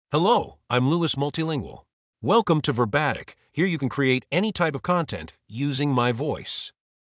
MaleEnglish (United States)
Lewis MultilingualMale English AI voice
Lewis Multilingual is a male AI voice for English (United States).
Voice sample
Listen to Lewis Multilingual's male English voice.